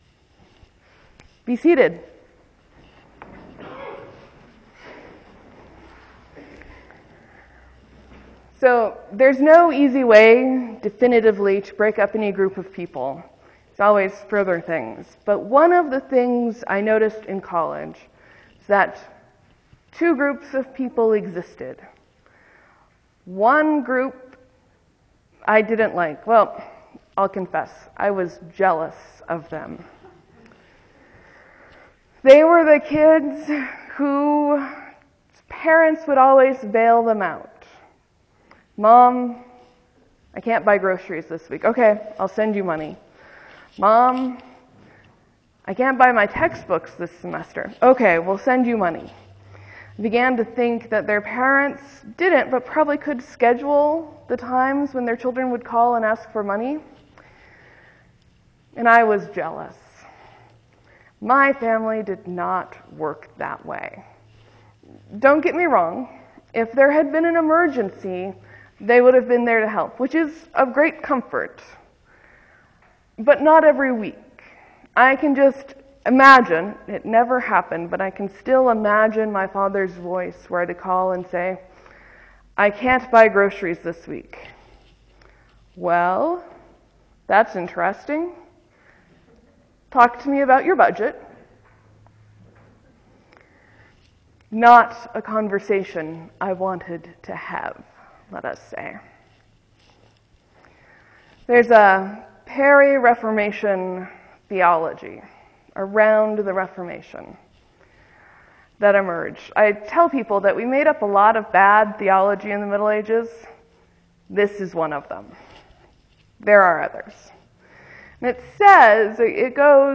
Budgeting Grace, All Saints sermon 2013